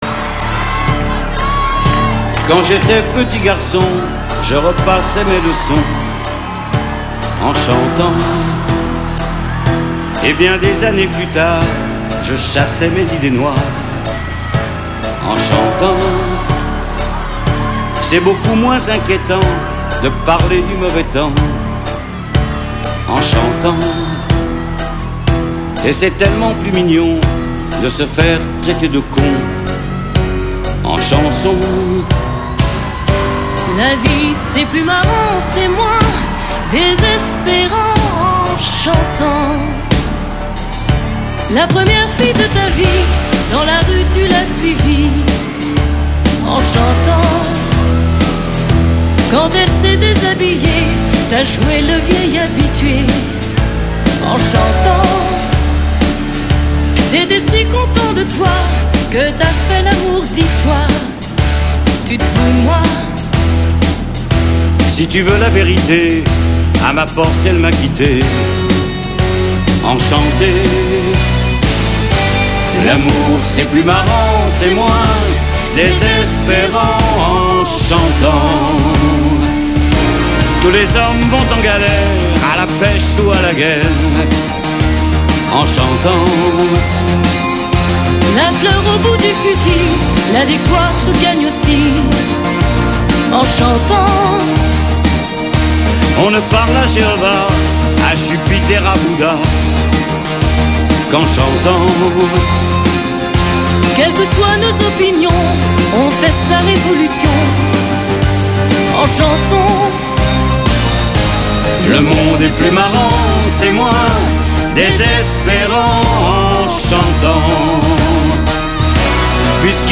DUOS